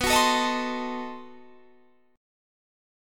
Listen to B7b9 strummed